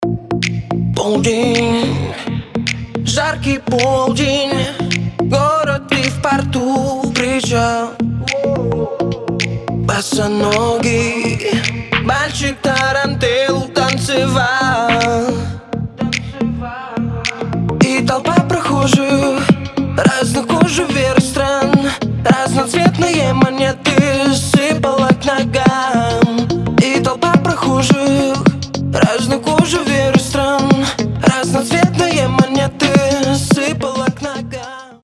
• Качество: 320, Stereo
deep house
Dance Pop
Cover